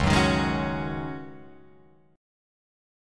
C#sus2sus4 chord